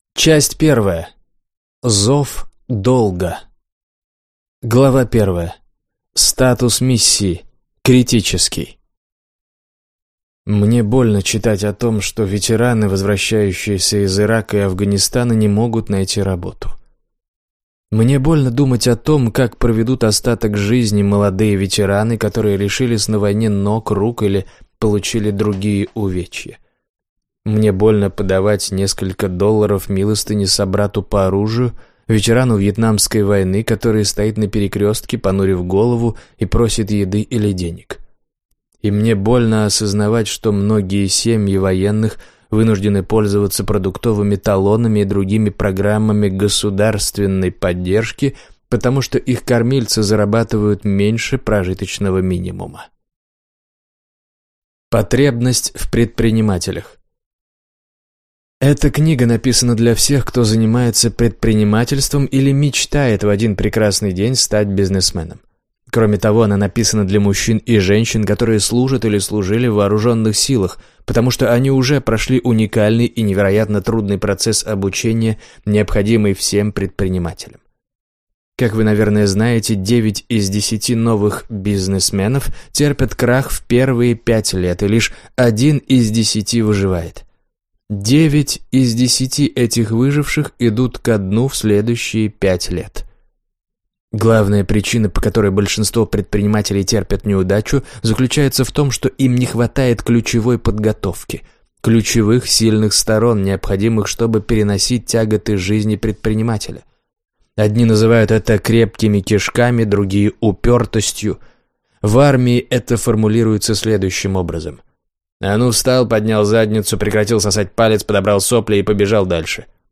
Аудиокнига 8 уроков лидерства. Чему военные могут научить бизнес-лидеров - купить, скачать и слушать онлайн | КнигоПоиск